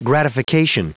Prononciation du mot gratification en anglais (fichier audio)
Prononciation du mot : gratification